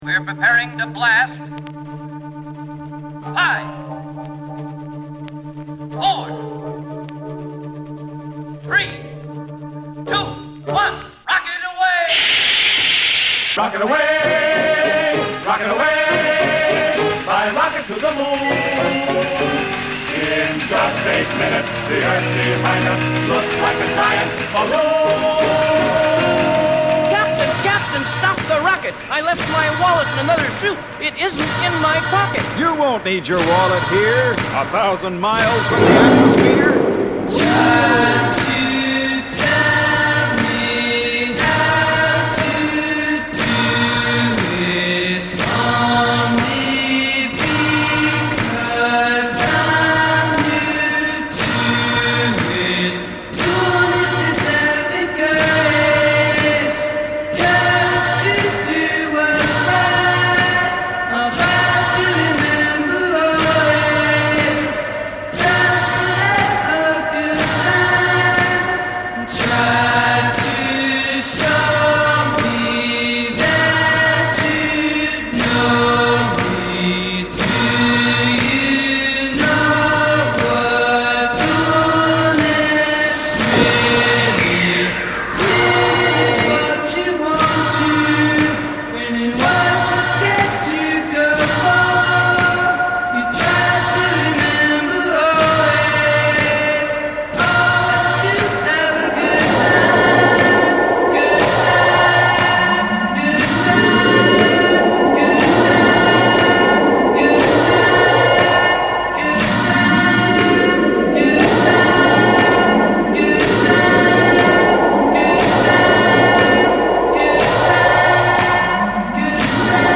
A collection of tunes I discovered in 2007